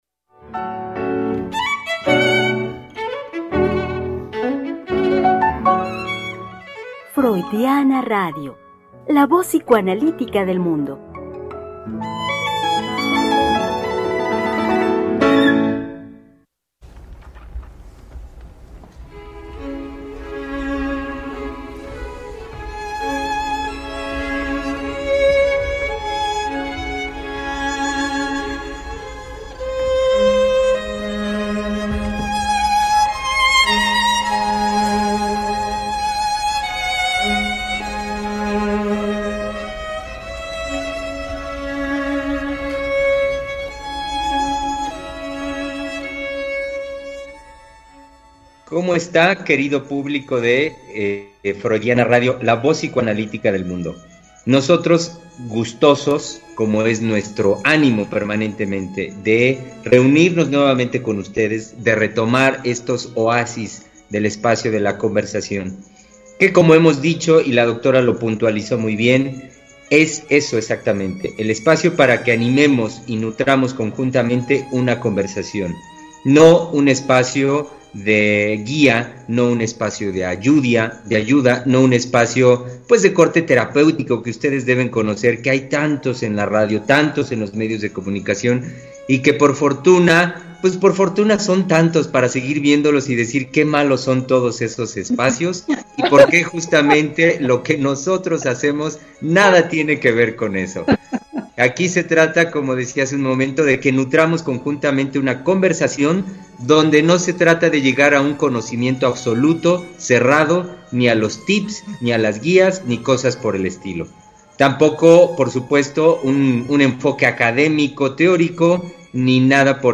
Tres Mujeres Psicoanalistas Hablando de la Vida cotidiana.
Programa transmitido el 25 de junio del 2020.